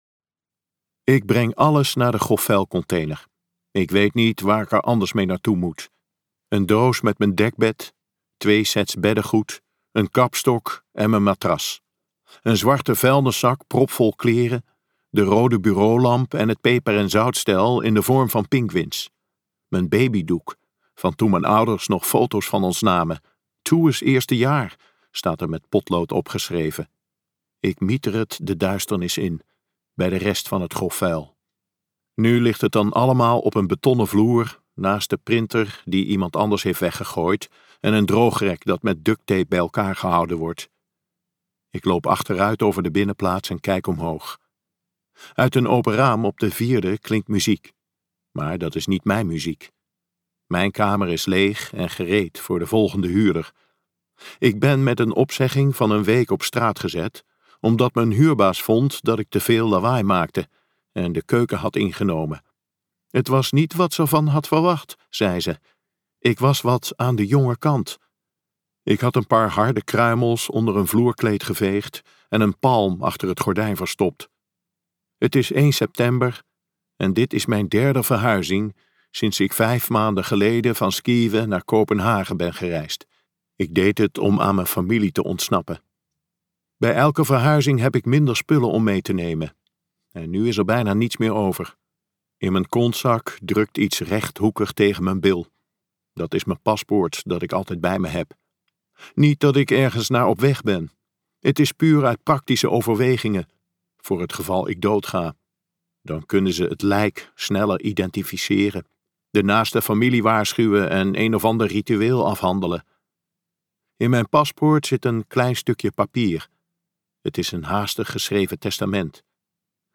Je had er waarschijnlijk bij moeten zijn luisterboek | Ambo|Anthos Uitgevers